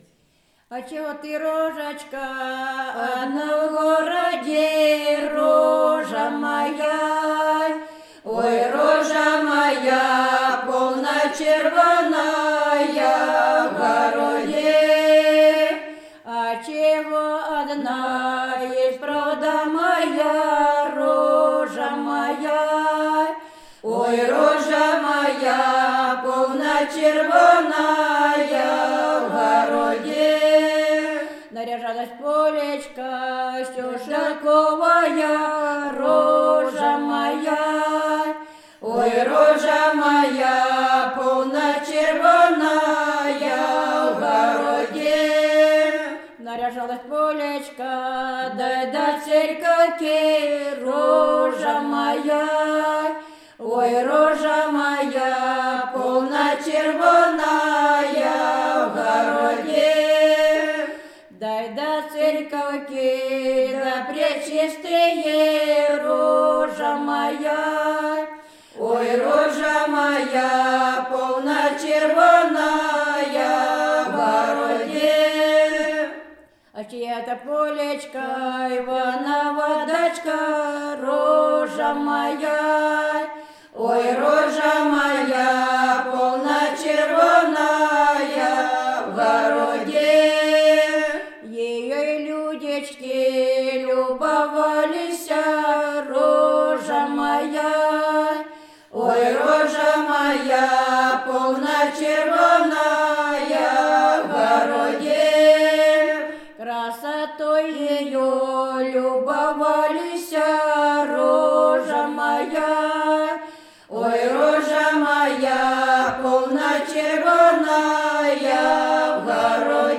01 Щедровная песня «Да чего ты, рожечка, одна в городе?» в исполнении жителей д. Камень Стародубского р-на Брянской обл.